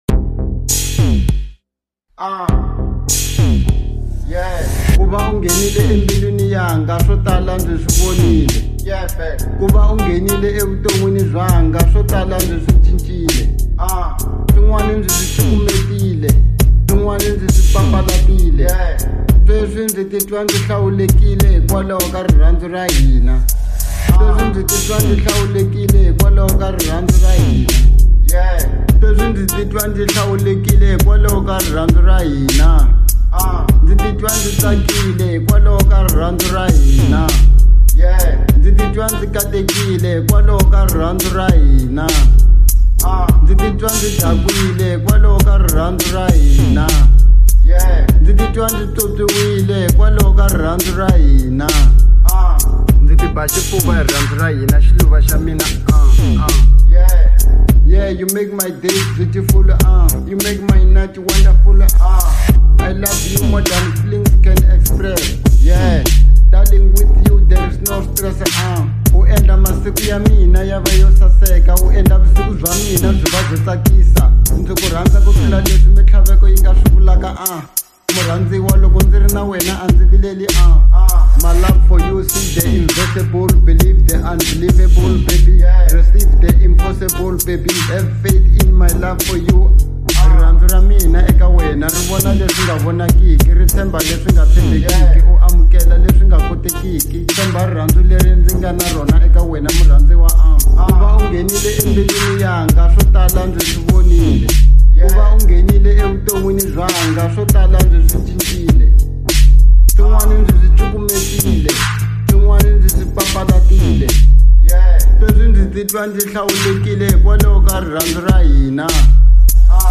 02:26 Genre : Hip Hop Size